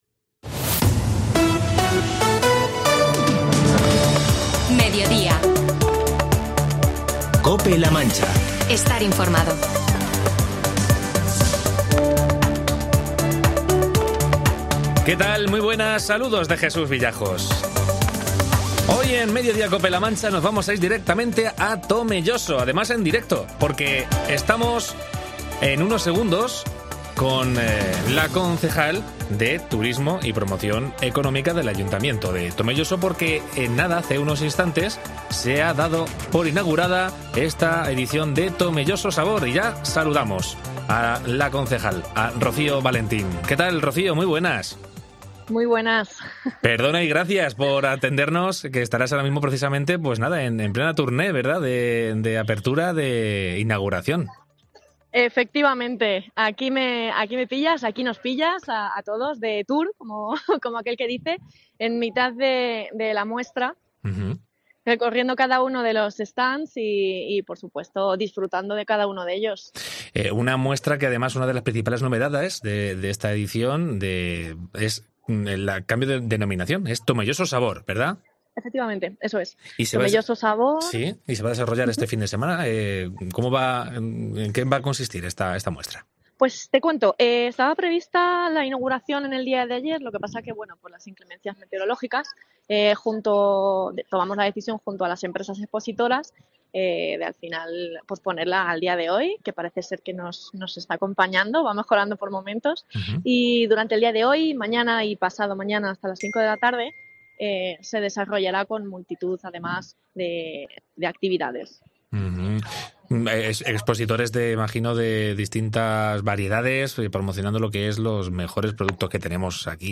Entrevista a Rocío Valentín, concejal de Promoción Económica del Ayuntamiento de Tomelloso